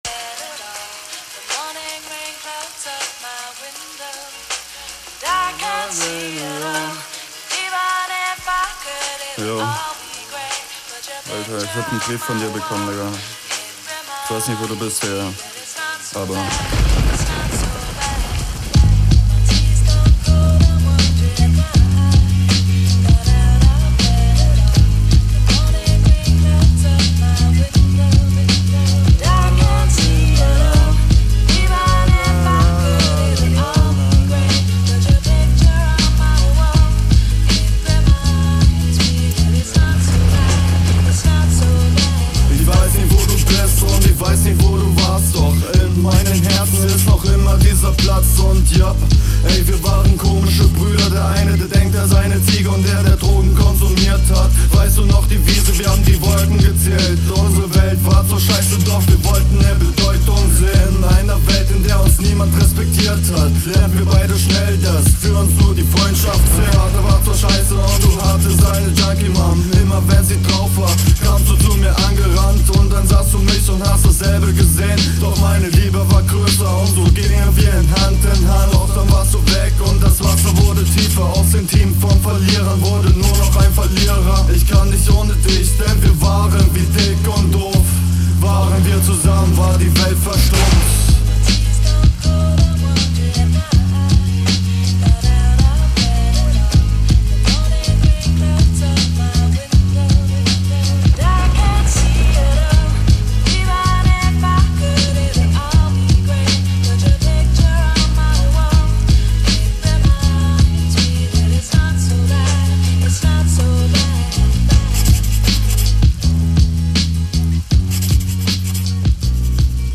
Flow: guter flow ich mag das wo du so geflowt hast, musikalisch und stimmeinsatz passt …
Der Stimmeinsatz ist wieder schön überheblich, die Delivery ist eher okay, flowlich sind da wohl …